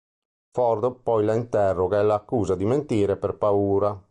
Pronounced as (IPA) /paˈu.ra/